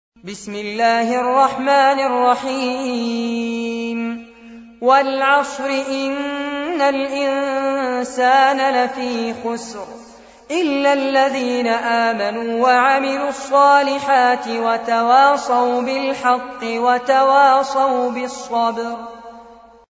أحد أشهر قراء القرآن الكريم في العالم الإسلامي، يتميز بجمال صوته وقوة نفسه وإتقانه للمقامات الموسيقية في التلاوة.
تلاوات المصحف المجود